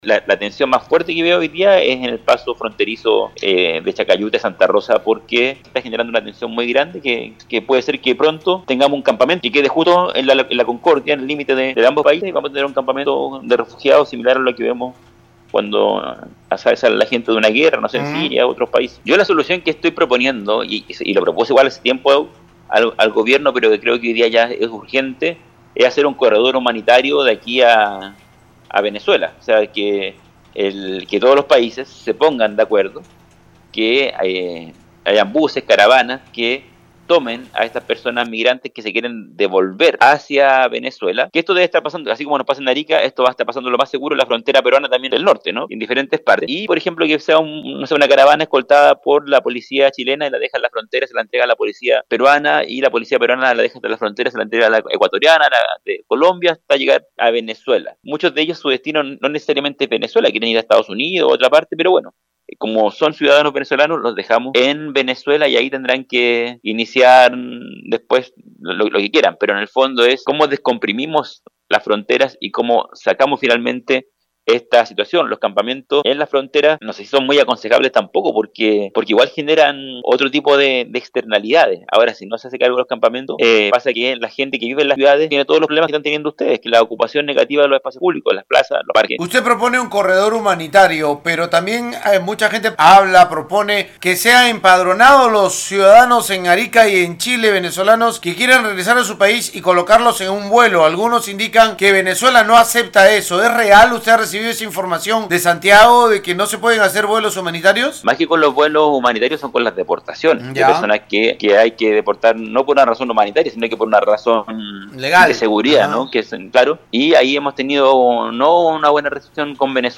alcalde-de-arica-gerardo-espindola.mp3